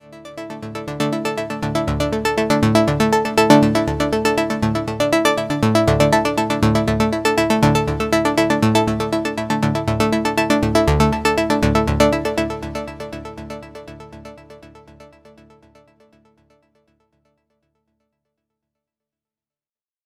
Arp-1.mp3